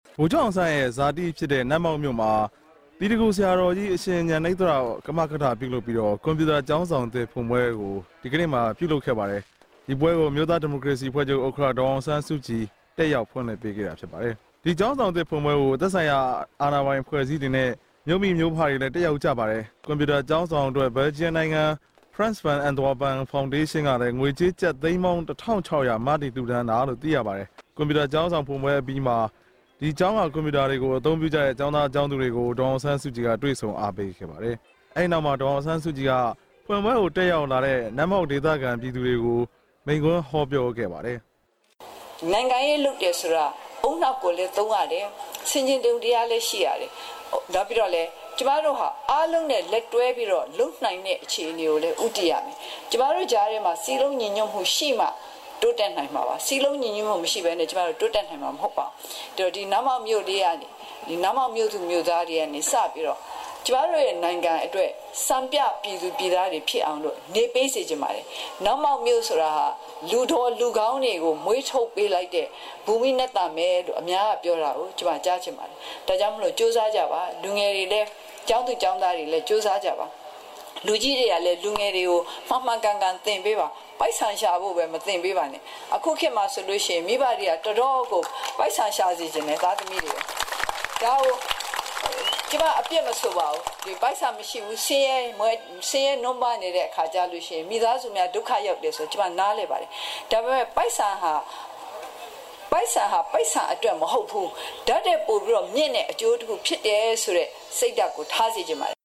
ဖခင်ဖြစ်သူရဲ့ ဇာတိဖြစ်တဲ့ မကွေးတိုင်း နတ်မောက်မြို့မှာကျင်းပတဲ့ ဗိုလ်ချုပ်အောင်ဆန်း အထိမ်းအမှတ် “အောင်ဆန်း ကွန်ပျူတာ ကျောင်းဆောင်သစ်” ဖွင့်ပွဲနဲ့ လှူဒါန်းပွဲကို ဒီကနေ့တက်ရောက်စဉ် အမျိုးသားဒီမိုကရေစီအဖွဲ့ချုပ် ဥက္ကဌ ဒေါ်အောင်ဆန်းစုကြည်က အခုလို ပြောလိုက်တာဖြစ်ပါတယ်။